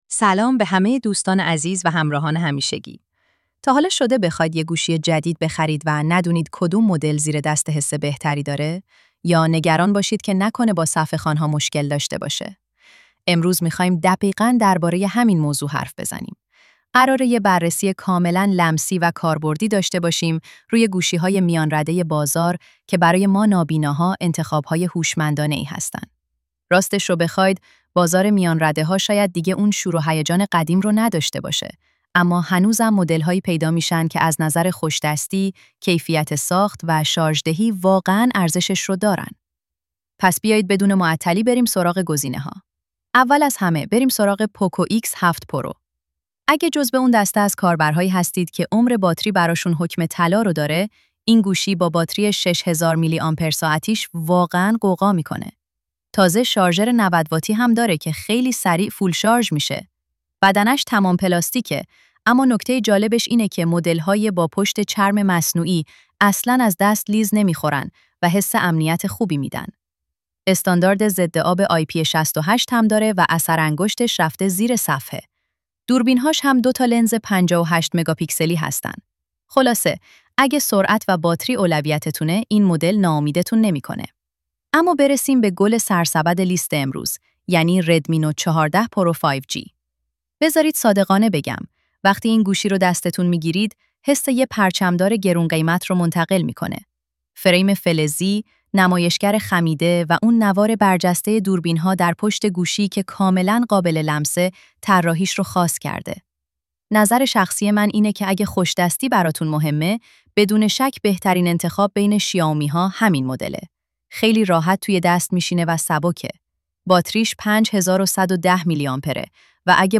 گوینده هوش مصنوعی